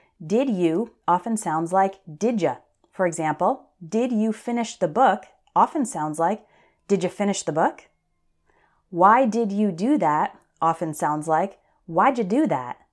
One reason for the difficulty is that when native English speakers are talking fast, we often change how we pronounce the words at the beginning of questions.
DID YOU >> DIDJA